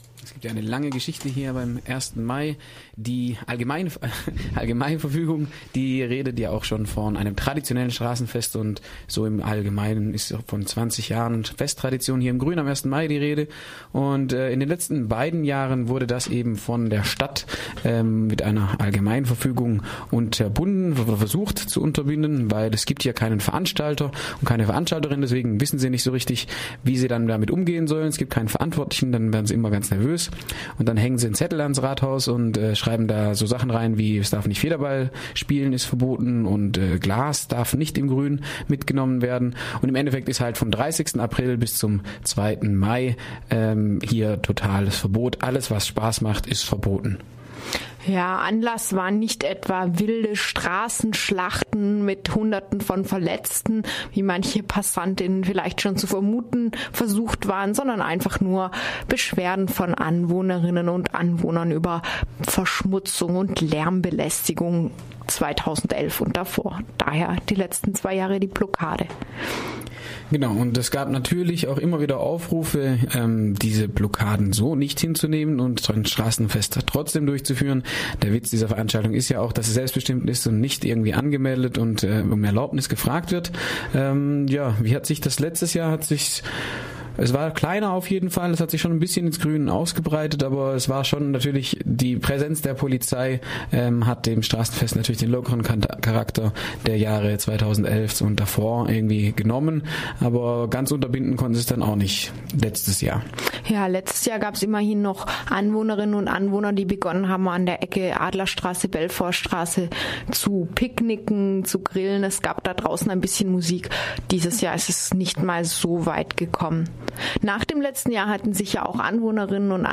21.10.2013 Ein Bericht mit O-Tönen aus der antimilitaristischen Fahrraddemo, die am Samstag, 19.10. in Freiburg stattfand. Veranstaltet wurde die Demo vom Arbeitskreis gegen Krieg und Militarisierung (AKM).